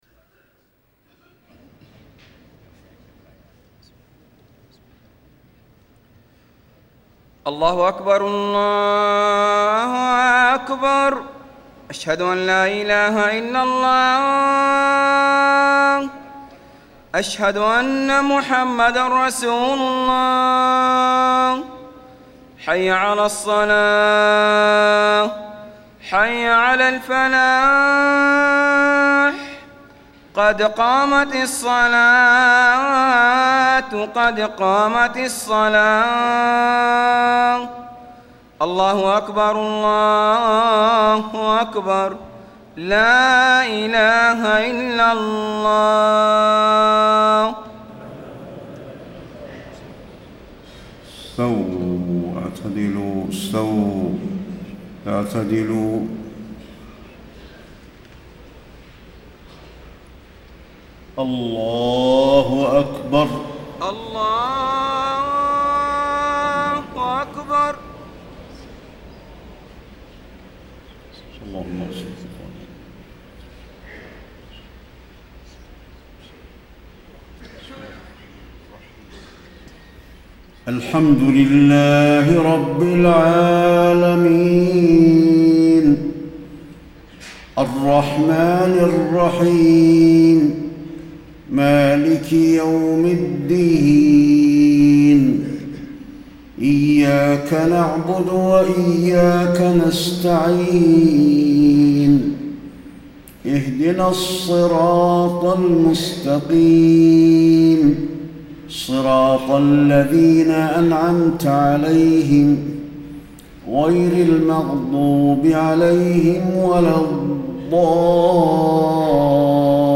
صلاة العشاء 3-7-1434هـ سورتي التكوير و العصر > 1434 🕌 > الفروض - تلاوات الحرمين